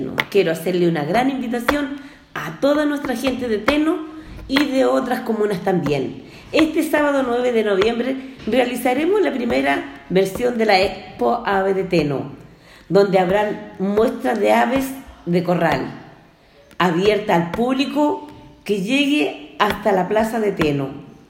Cuña_AlcaldesaSandraAmestica.m4a